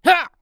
CK普通2.wav 0:00.00 0:00.43 CK普通2.wav WAV · 37 KB · 單聲道 (1ch) 下载文件 本站所有音效均采用 CC0 授权 ，可免费用于商业与个人项目，无需署名。
人声采集素材/男2刺客型/CK普通2.wav